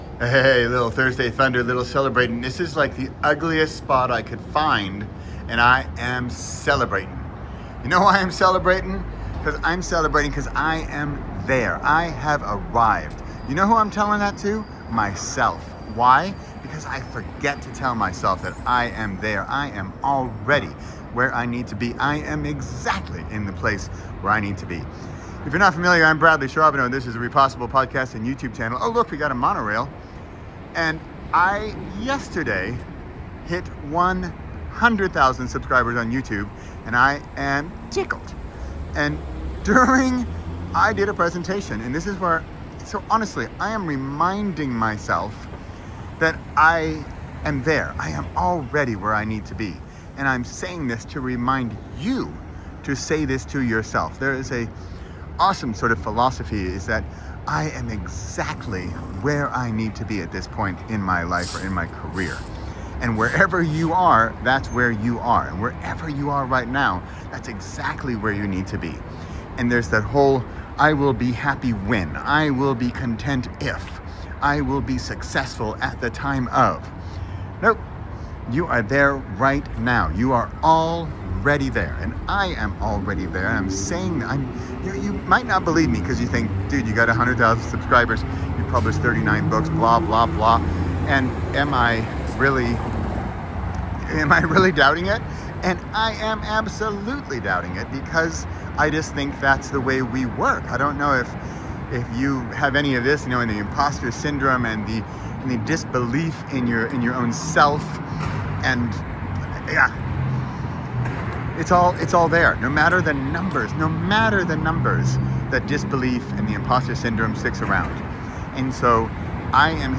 re477: You’re Already There (Thursday Thunder from Las Vegas)